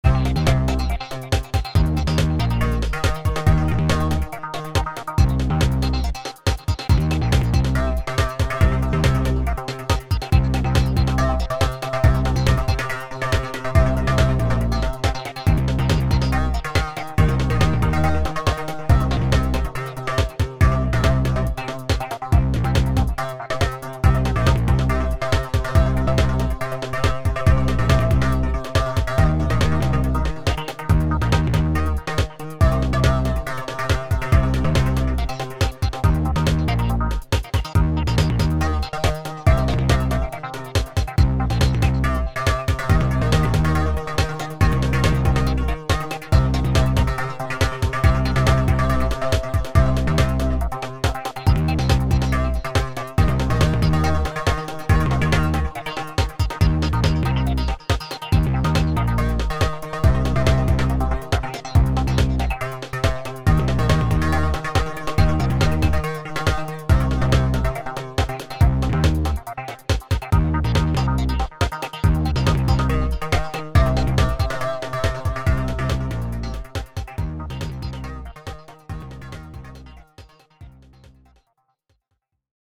On obtient comme résultat une musique assez inhabituelle, structurée mais sans thème répétitif.
Voici 3 ambiances musicales pour illustrer les capacités de la Société Henon.